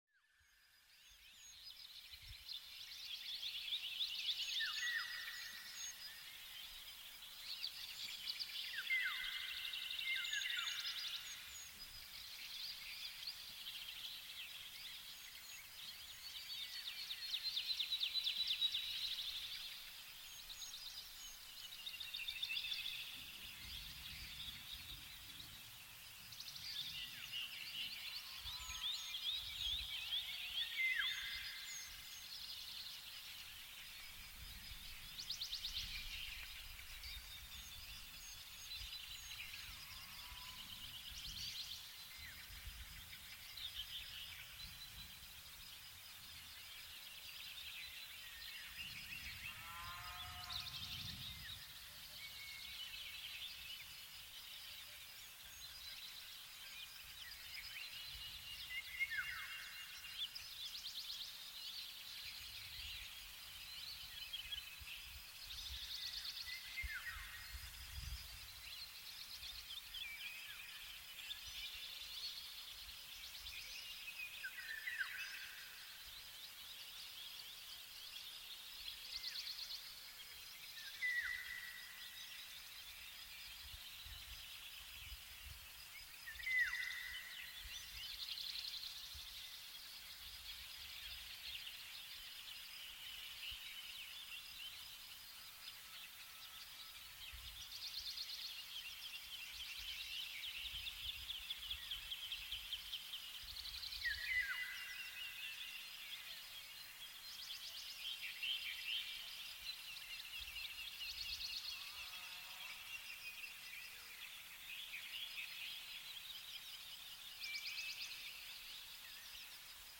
Blumenwiese-Frühlingsharmonie: Klänge voller entspannender Harmonie